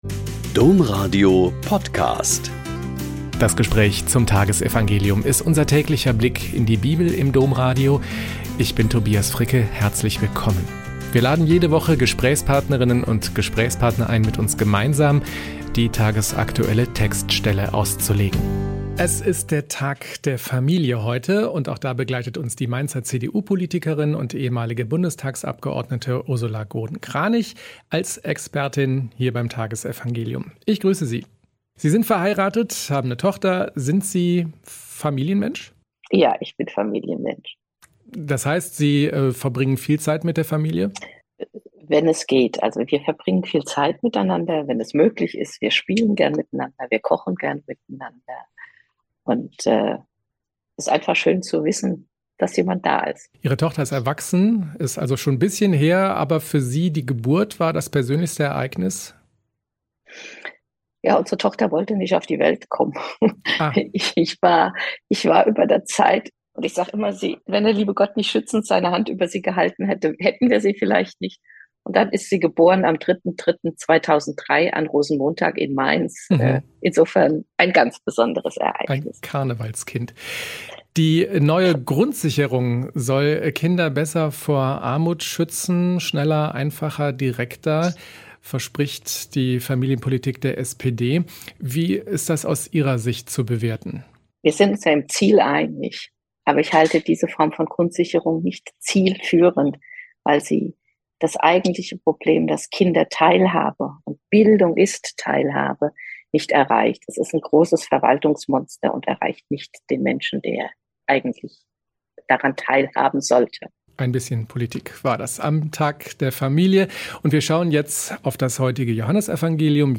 Joh 17,6a.11b-19 - Gespräch mit Ursula Groden-Kranich